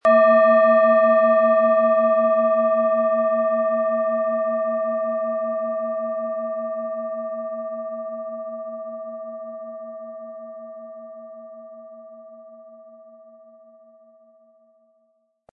Planetenschale® Geborgen fühlen & In den Bauch spüren mit Mond, Ø 11,1 cm, 100-180 Gramm inkl. Klöppel
Planetenton 1
Mit Klöppel, den Sie umsonst erhalten, er lässt die Planeten-Klangschale Mond voll und harmonisch erklingen.